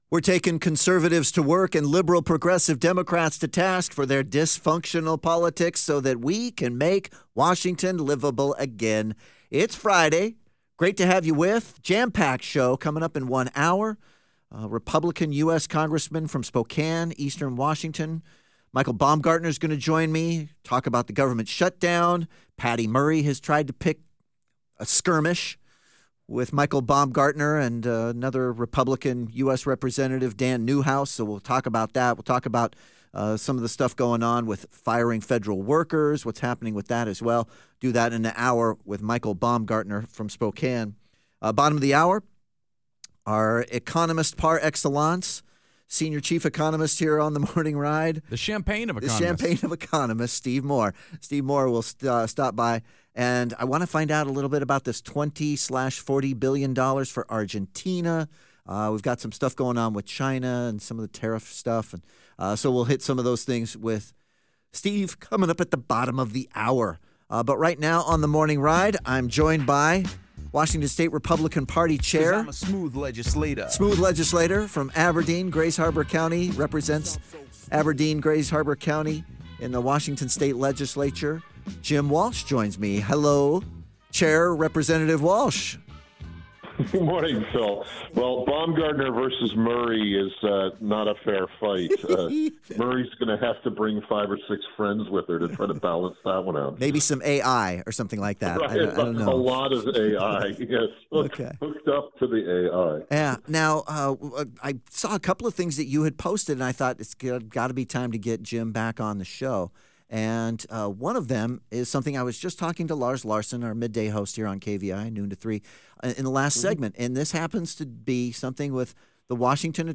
Catch the full conversation with Jim Walsh on The Morning Ride below: